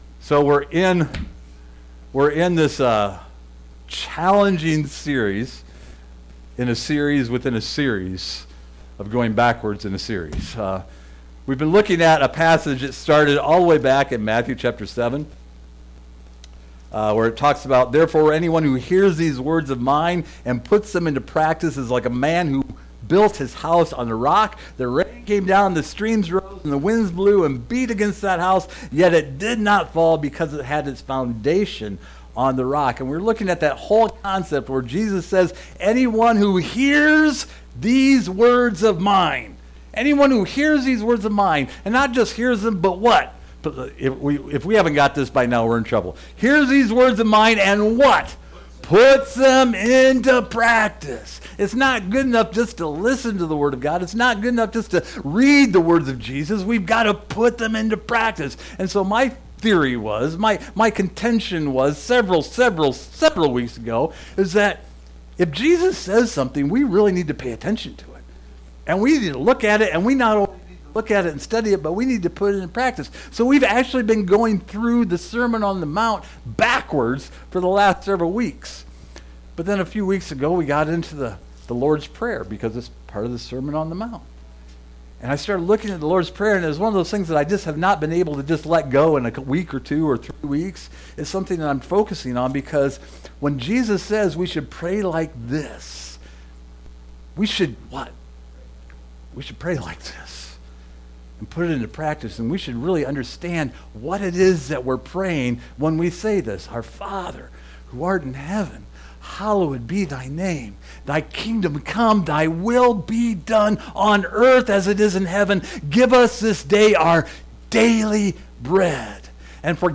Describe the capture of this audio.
Luke 11 Service Type: Sunday Morning Depending on which gospel we are reading from the Lord's prayer says forgive us our sins